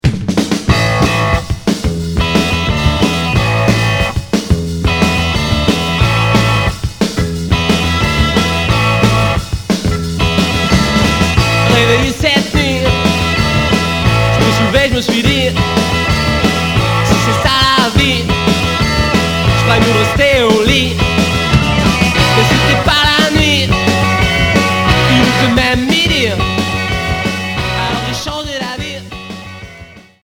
Garage Deuxième 45t retour à l'accueil